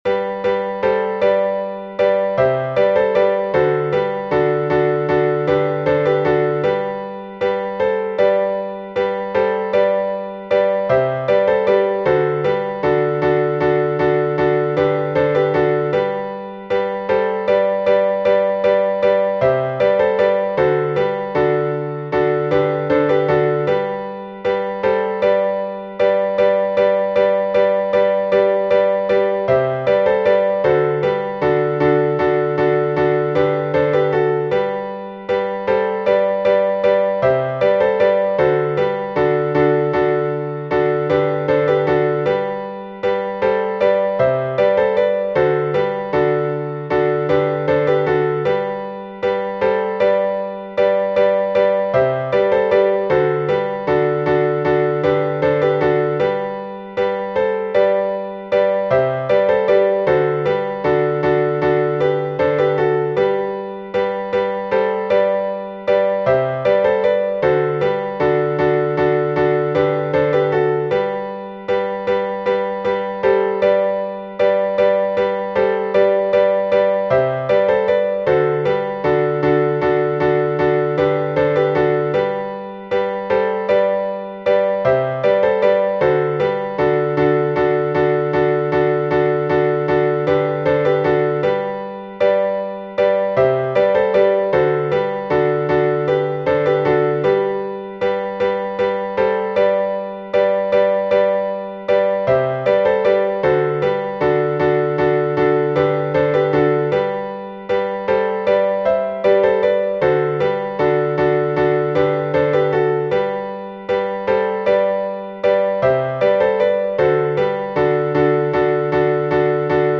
И самое главное — нотки известного напева этого канона.